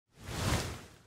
Added swoosh sounds.
blink_swoosh.1.ogg